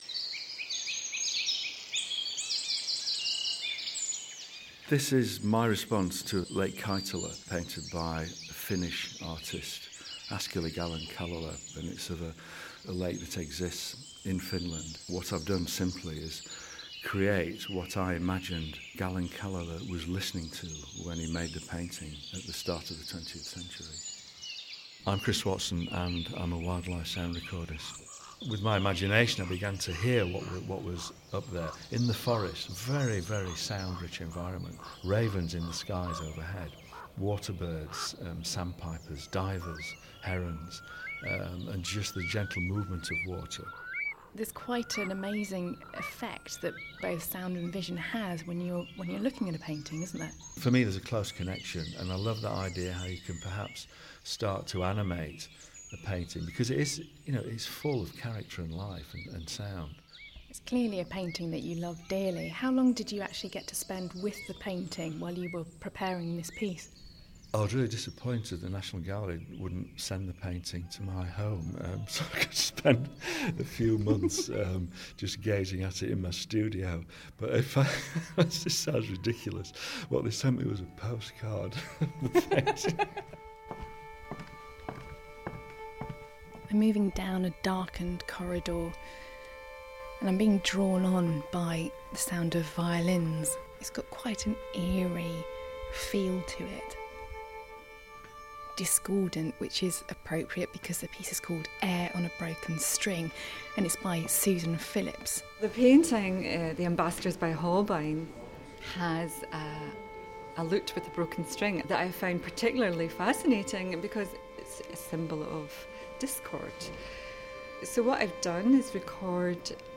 ...Wildlife sound recordist Chris Watson explains the appeal of Lake Keitele by Gallen-Kallela. He is one of six leading sound artists commissioned by The National Gallery to respond to a painting of their choice from the collection.